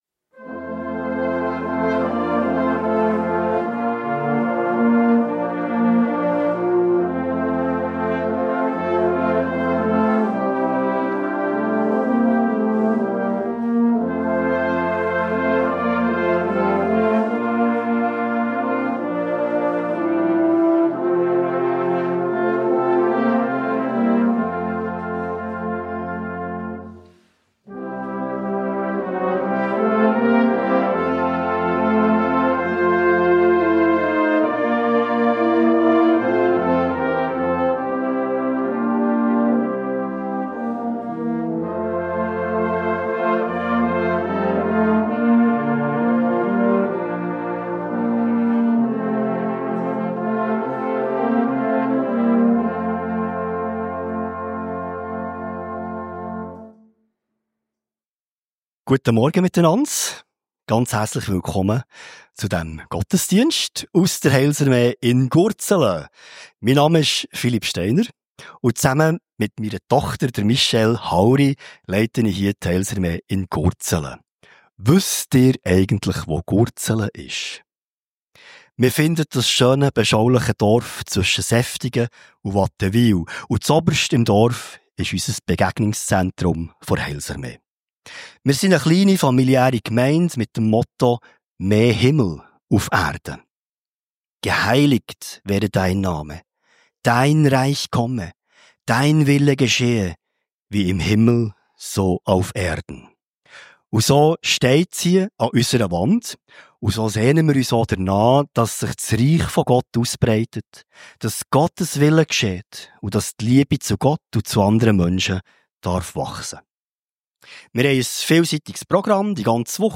Heilsarmee Gurzelen ~ Gottesdienst auf Radio BeO Podcast